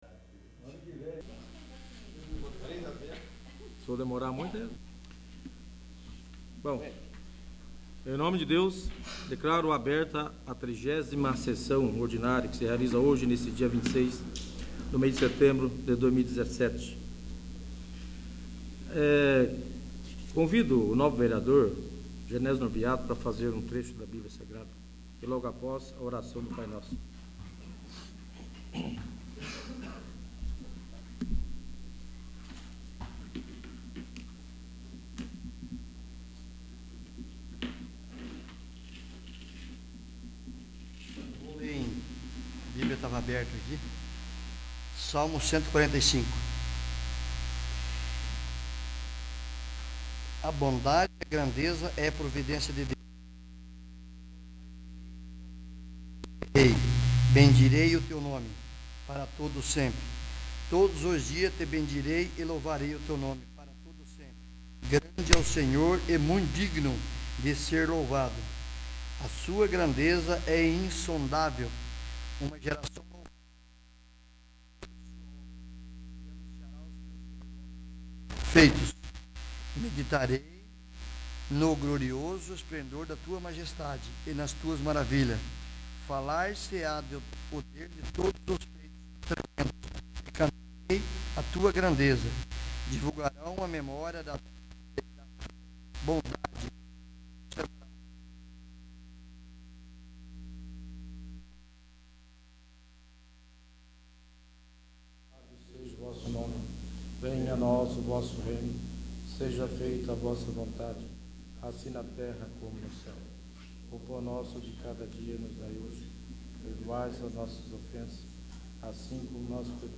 30º. Sessão Ordinária